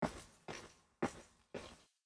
steps-walking.m4a